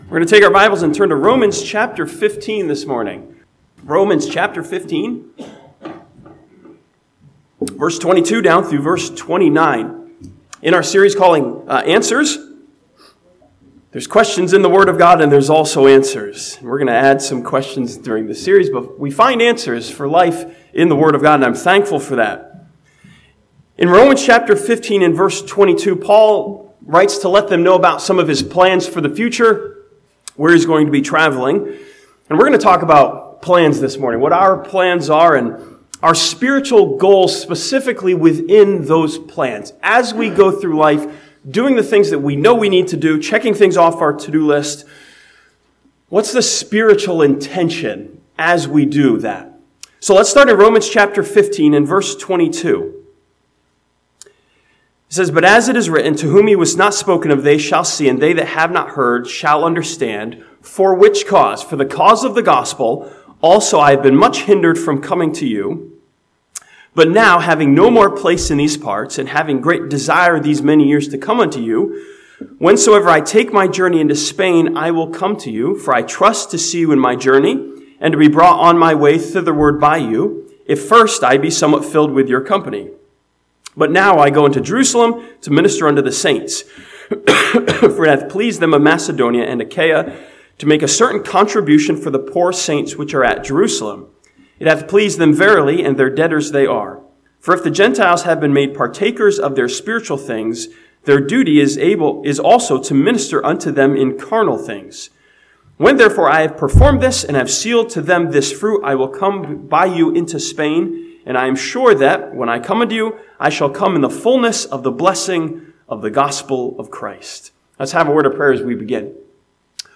This sermon from Romans chapter 15 asks a question of intention: what are we planning to do for the Lord?